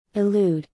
But when you’re saying them slowly, the first syllable of “allude” has an “ah” sound, while the first syllable of “elude” has an “eh” sound.